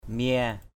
/mia/ mia m`% [Cam M] (d.) khía, bánh răng = dents d’engrenage. gear teeth. mia patak m`% ptK khía xa cán bông = système d’engrenage de l’égreneuse à coton.